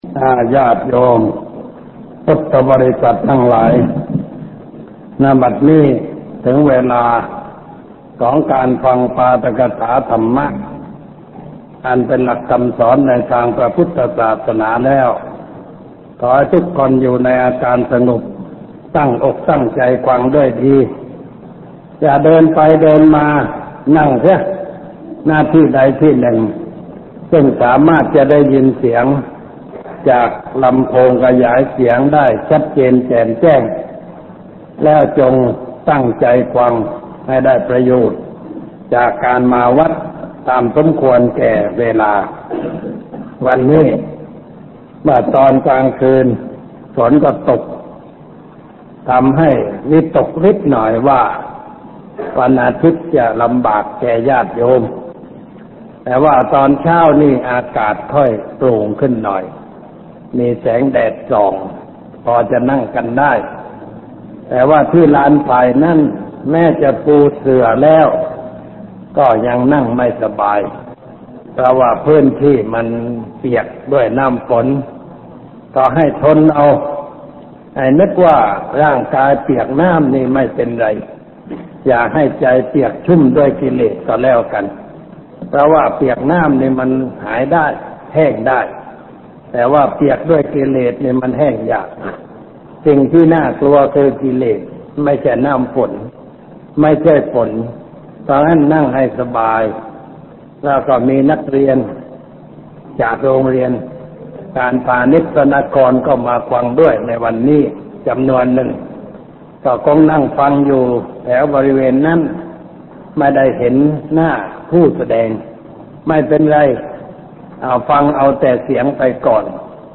พระพรหมมังคลาจารย์ (ปัญญานันทภิกขุ) - ธรรมะทำให้เป็นคนสมบูรณ์
ฟังธรรมะ Podcasts กับ พระพรหมมังคลาจารย์ (ปัญญานันทภิกขุ)